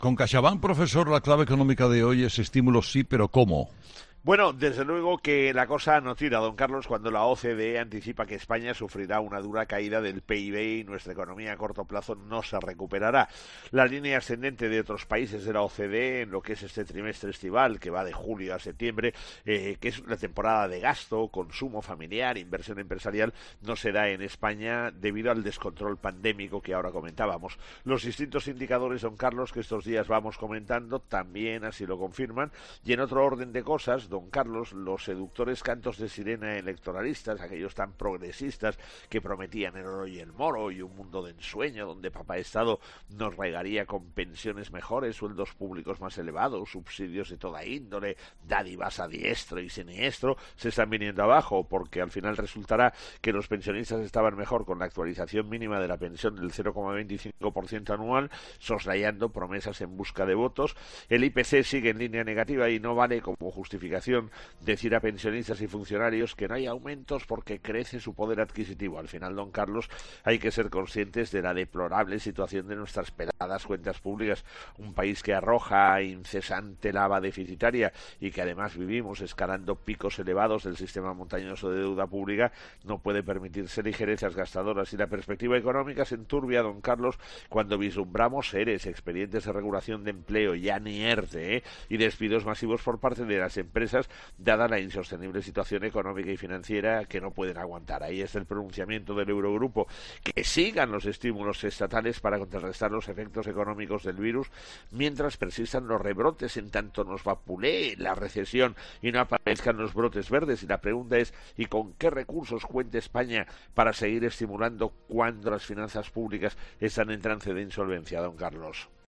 El profesor José María Gay de Liébana analiza en 'Herrera en COPE’ las claves económicas del día.